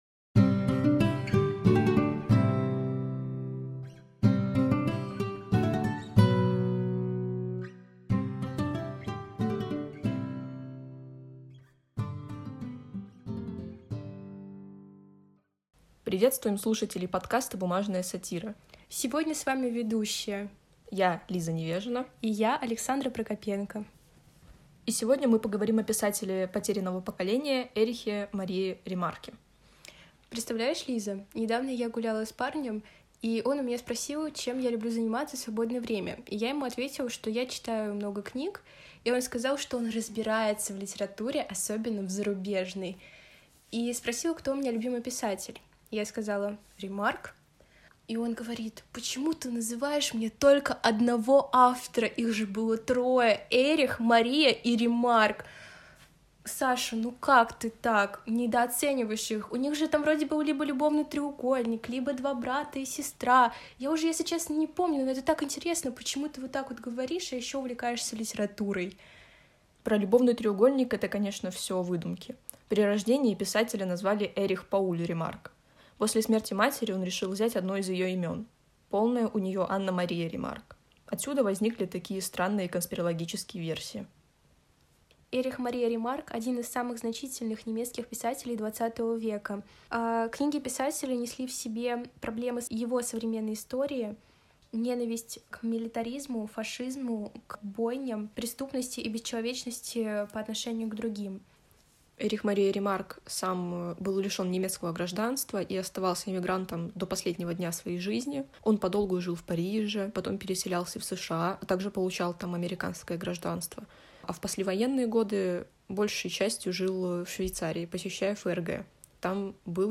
В новом выпуске студенты решили порассуждать о великом немецком писателе ХХ века – Эрихе Марии Ремарке.
Они рассказывают об авторе «потерянного поколения», делятся интересными фактами о некоторых известных произведениях писателя, отвечают на вопросы: почему Ремарка не любило немецкое правительство и почему ему пришлось эмигрировать. В конце эпизода ведущие дают советы, что почитать из Ремарка.